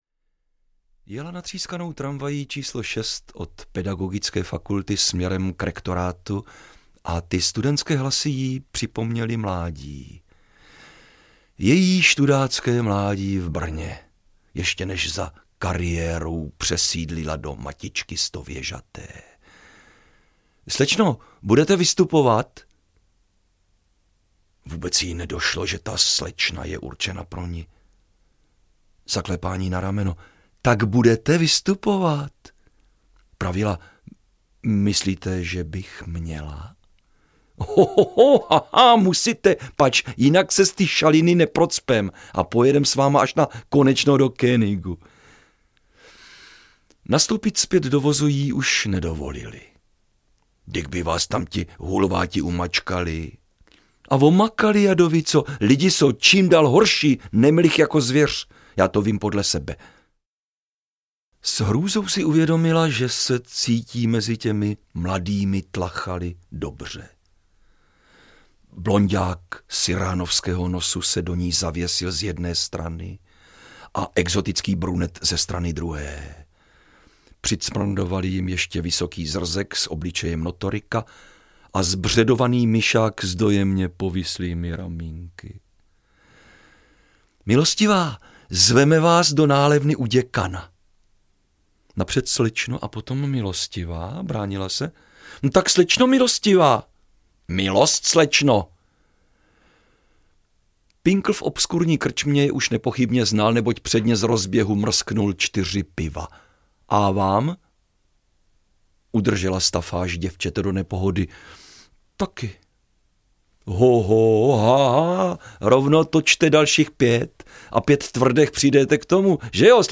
Slečno, budete vystupovat? audiokniha
Ukázka z knihy
slecno-budete-vystupovat-audiokniha